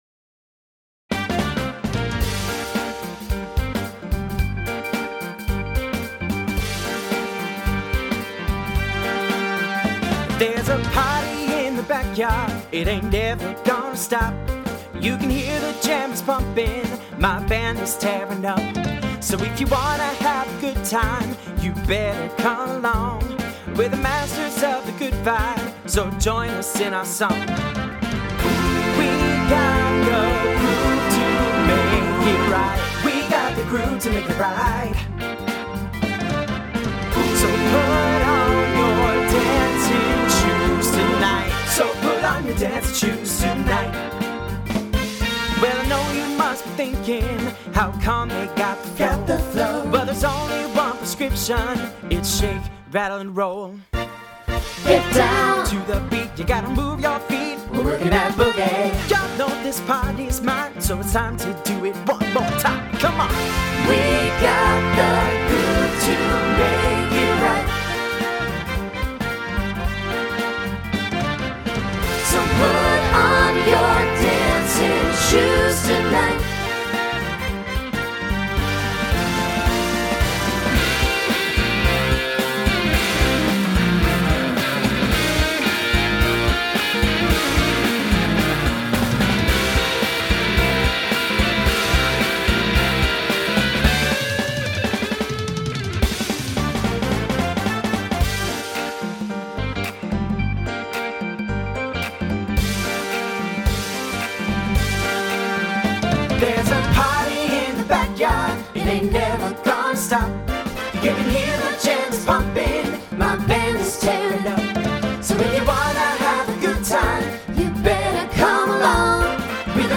SATB
TTB Instrumental combo Genre Pop/Dance